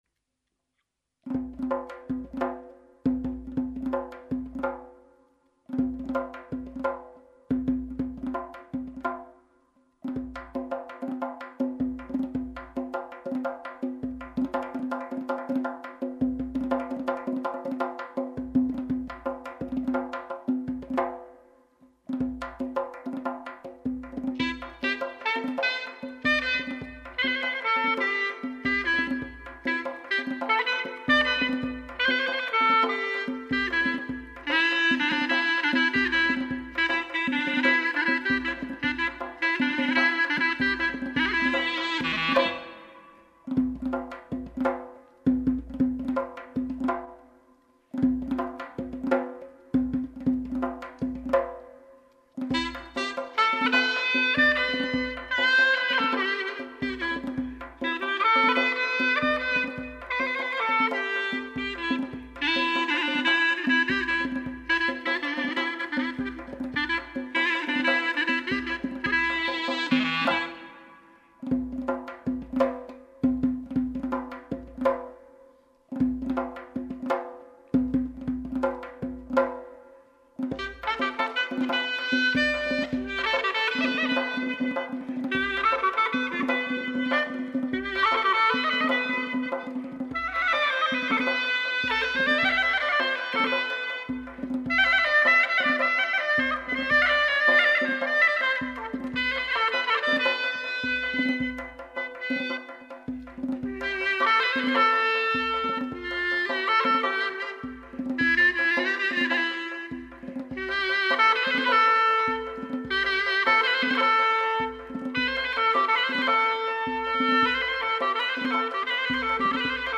イラン音楽；イランのクラリネットの第一人者による演奏（２）【音声】